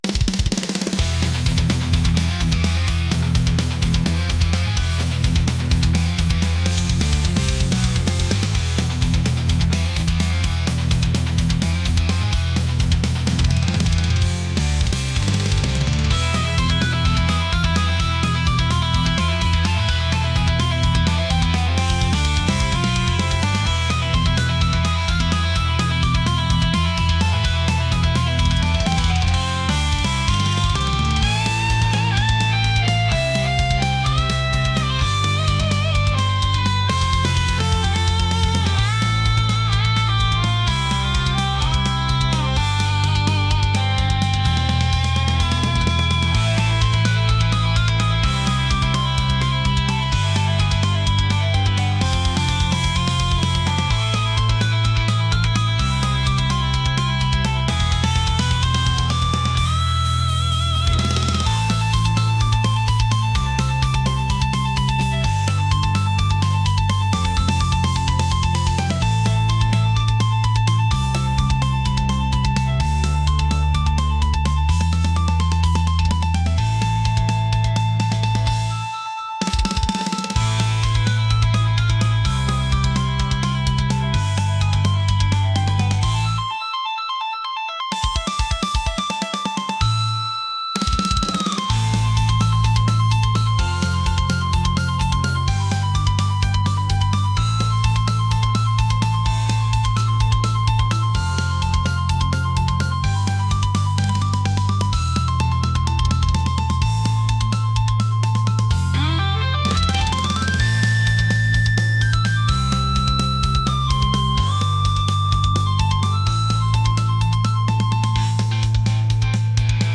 heavy | metal | aggressive